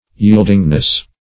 Yield"ing*ness, n.